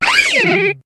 Cri de Dimoret dans Pokémon HOME.